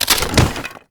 woodSnap.ogg